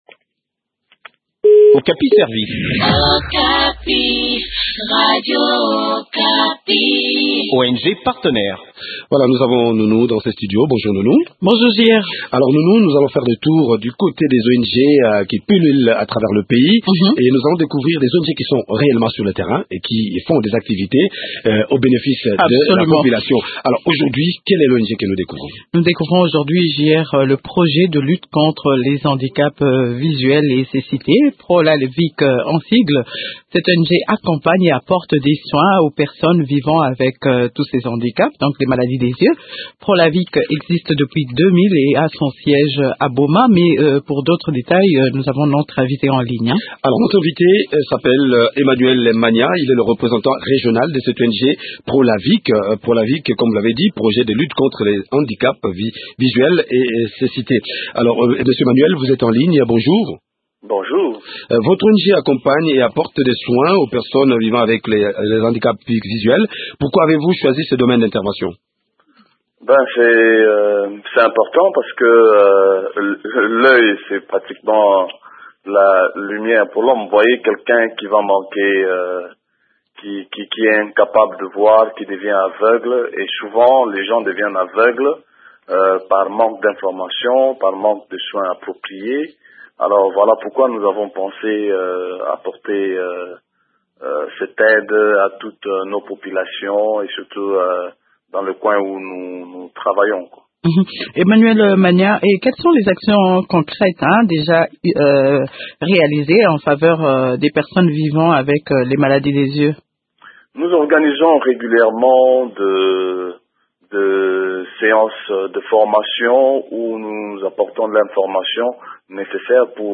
Le point sur les activités de cette ONG dans cet entretien